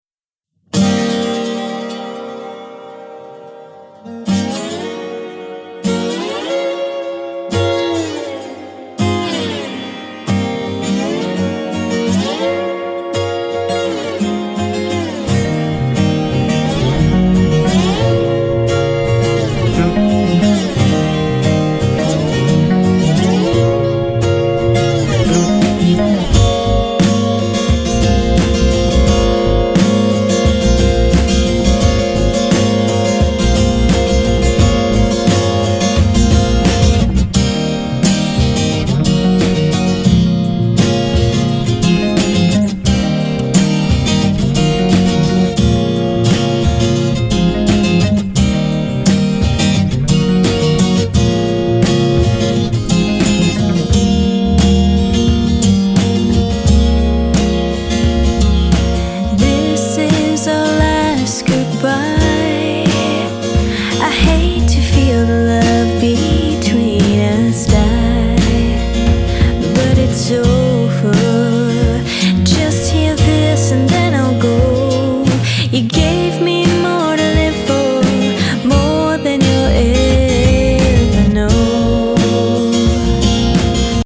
Guitar, bass guitar
/ Outro. 87 bpm.